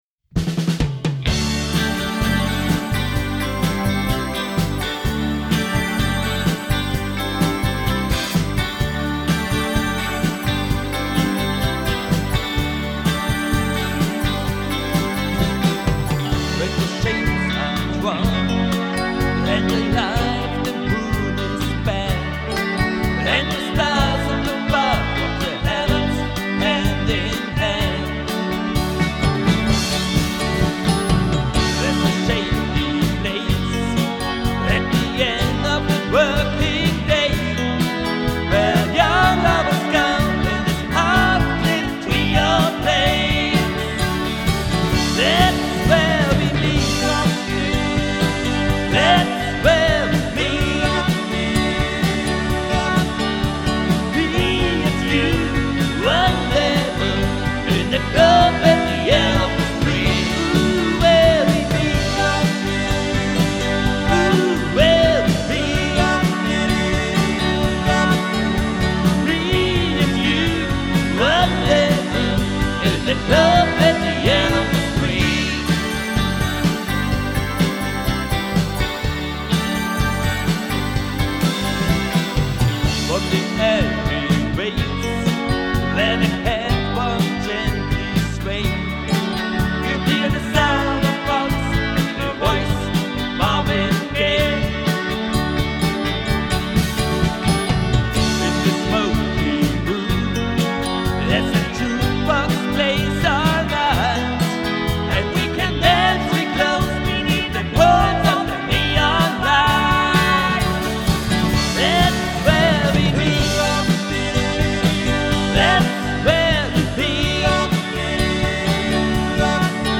Ihre Hochzeitsband.